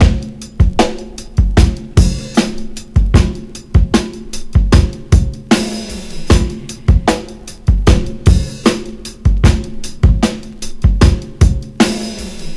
• 76 Bpm 2000s Drum Loop F Key.wav
Free drum beat - kick tuned to the F note. Loudest frequency: 724Hz